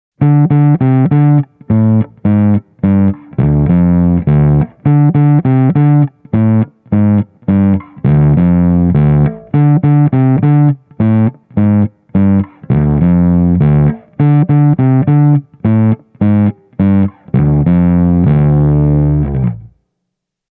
• Audio interface used: Universal Audio Volt 2
Särön luonne on Bass 200:ssa enemmän ”old school” -tyylisesti kermainen ja keskialuevoittoinen, niin kuin 1960- ja 60-lukujen Ampegeissa.
Ja lopuksi vielä esimerkki Bass 200:n särösoundista, soitettuna lyhytskaalaisella Squier Vista Musicmaster -bassolla: